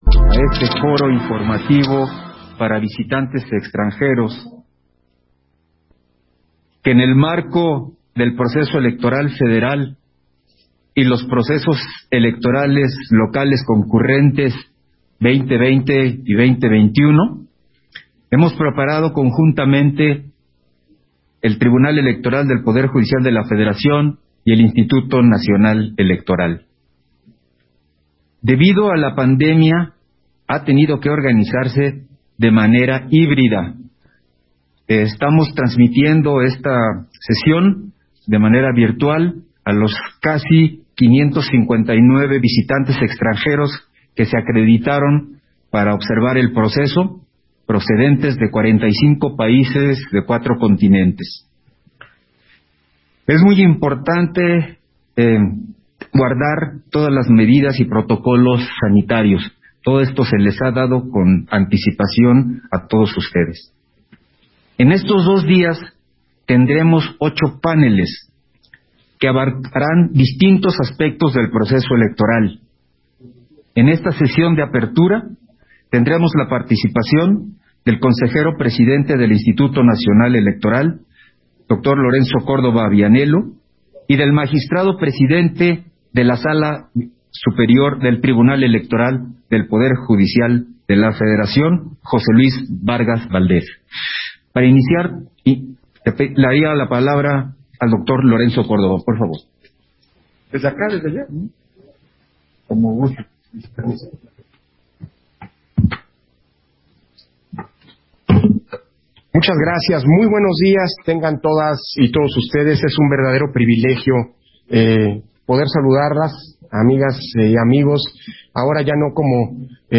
Versión estenográfica de la sesión de apertura del Foro Informativo para Visitantes Extranjeros